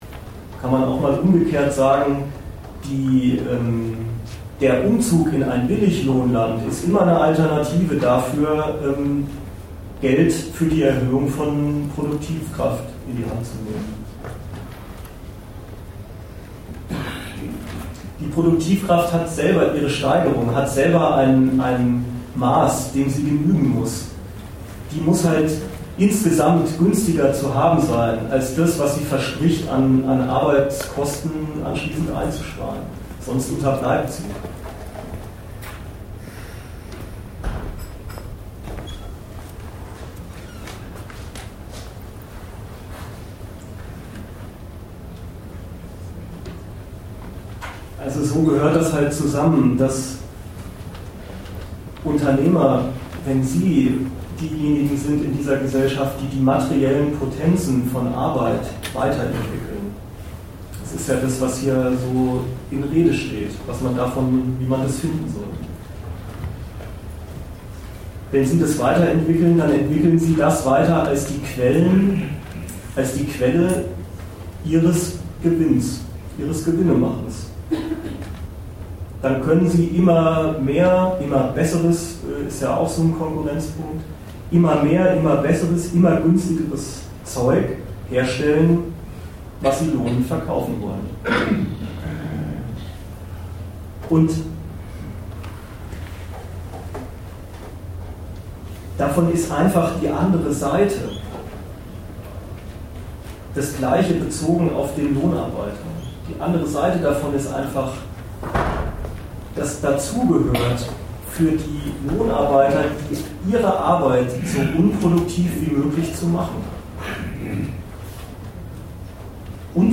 Ort Bremen
Dozent Gastreferenten der Zeitschrift GegenStandpunkt